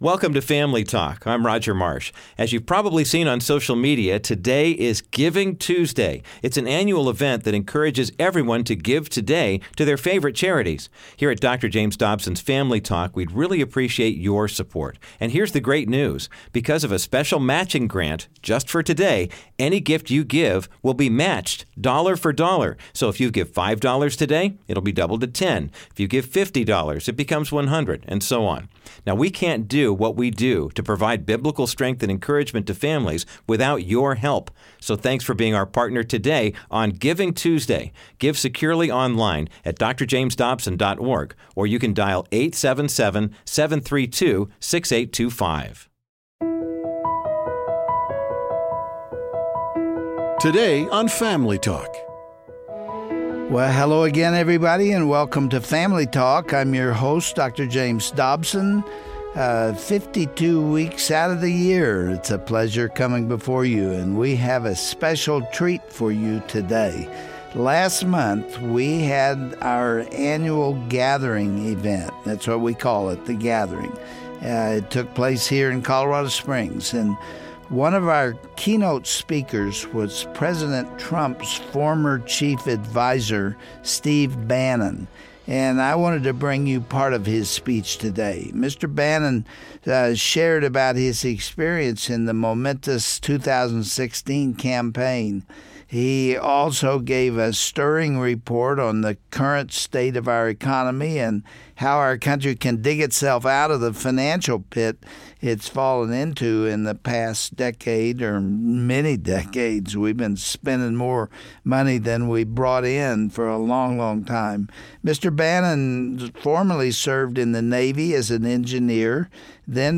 Family Talk is excited to bring you an exclusive address given by President Trumps former chief advisor Steve Bannon, when he spoke at a conference hosted by Dr. Dobson last month. Mr. Bannon shared his up-close account of the 2016 election and his opinions on how the country can pull itself out of the financial hole it has fallen into in the past decade.